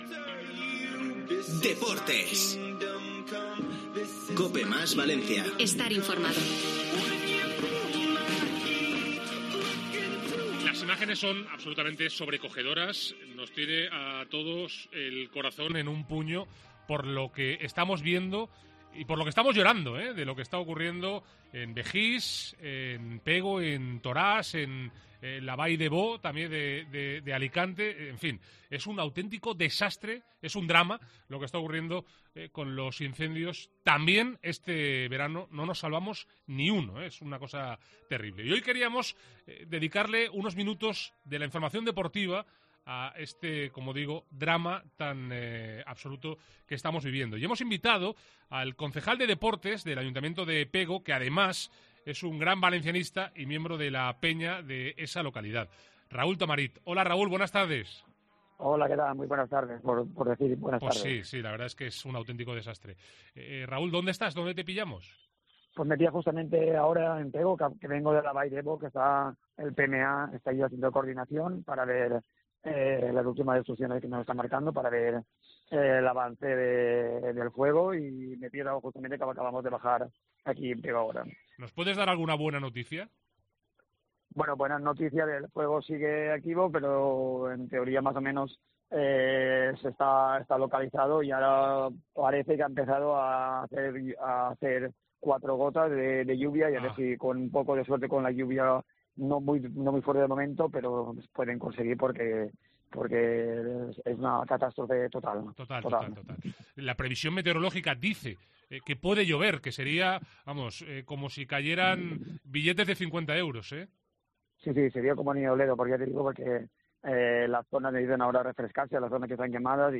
ENTREVISTA COPE
AUDIO. Entrevista al concejal de deportes de Pego desde la zona incendiada